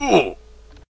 classic_hurt.ogg